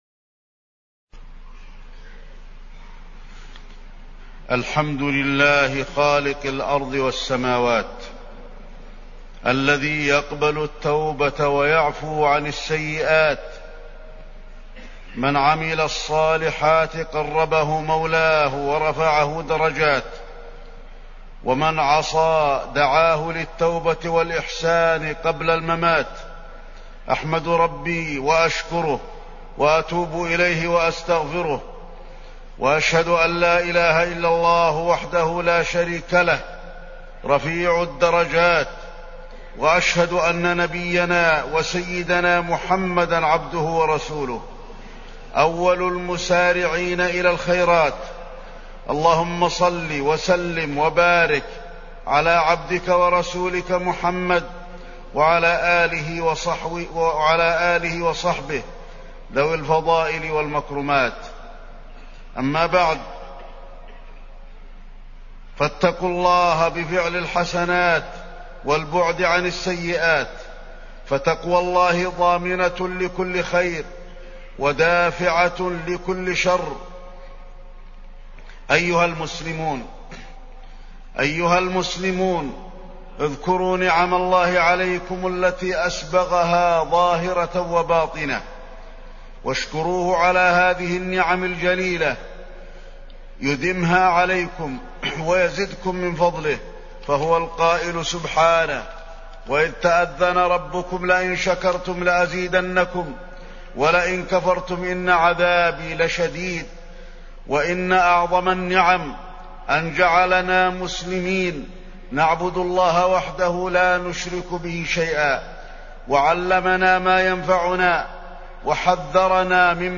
تاريخ النشر ٢٠ رمضان ١٤٢٧ هـ المكان: المسجد النبوي الشيخ: فضيلة الشيخ د. علي بن عبدالرحمن الحذيفي فضيلة الشيخ د. علي بن عبدالرحمن الحذيفي فضل العشر الأواخر من رمضان